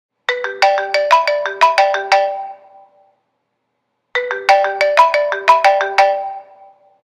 Standard Ringer